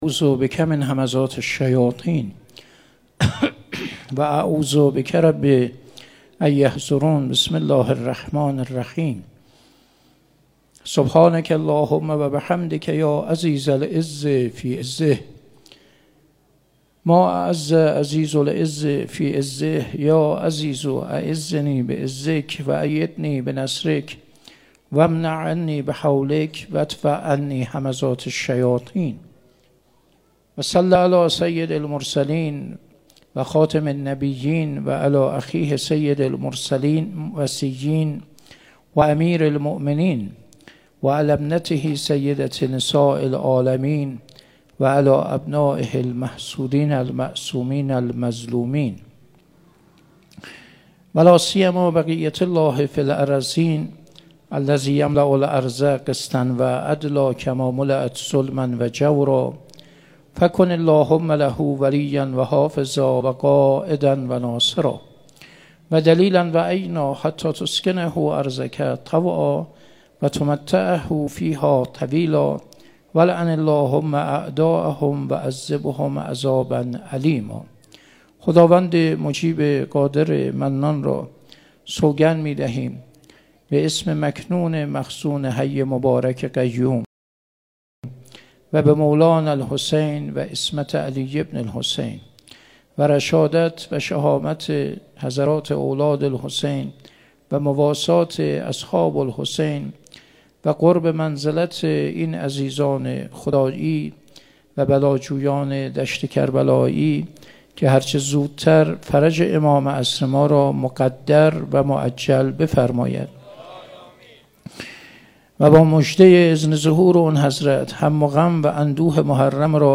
محرم1401 - سخنرانی